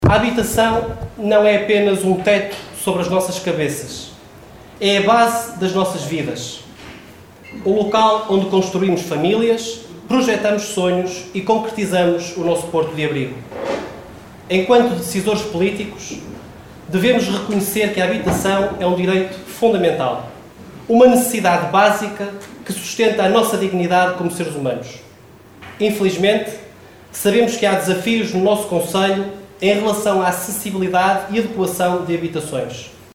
Terminadas as visitas, seguiu-se uma sessão protocolar na Biblioteca Municipal de Caminha, onde as várias entidades procederam às assinaturas dos contratos.
Rui Lages, Presidente da Câmara Municipal de Caminha, foi o primeiro a intervir, sublinhando que “habitação não é apenas um teto sobre as nossas cabeças, é muito mais do que isso”.